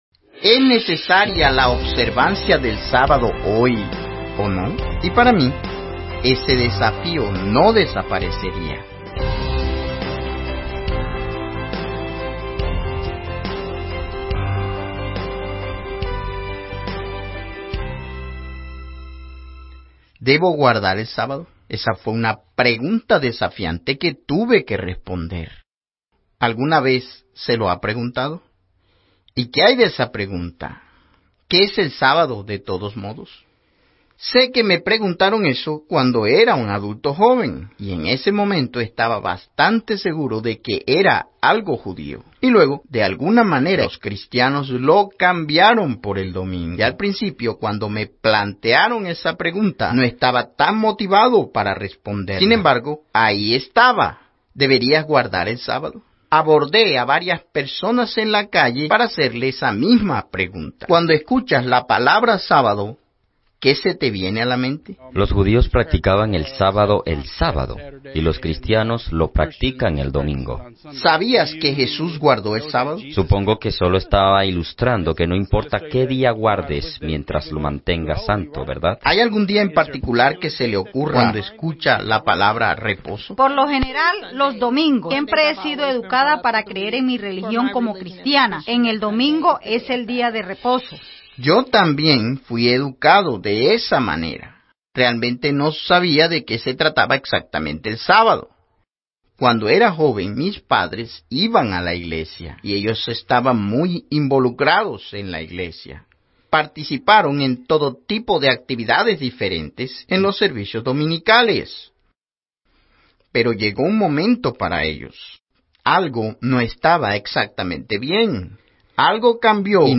Beyond Today programa de televisión ¿Debo guardar el sábado?